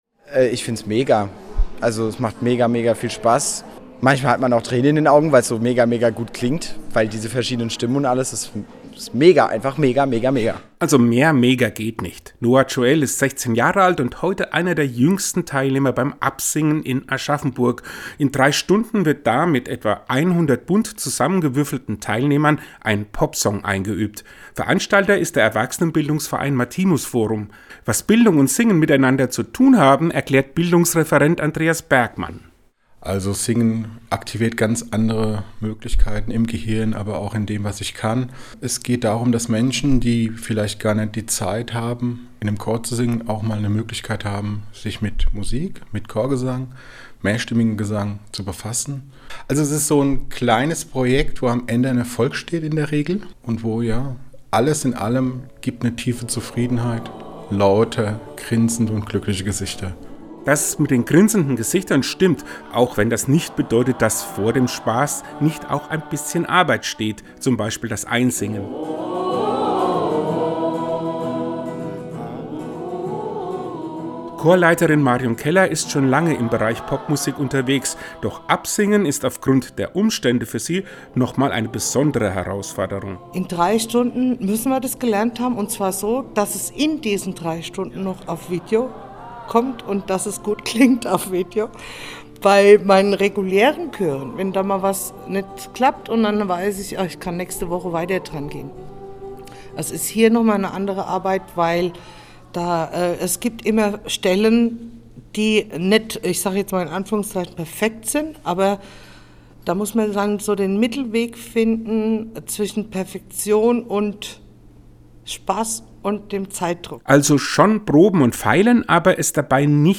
Sich einfach mal mit 100 Leuten treffen und miteinander einen Popsong singen – das klingt ungewöhnlich, ist in Aschaffenburg aber jetzt schon zum fünften Mal passiert.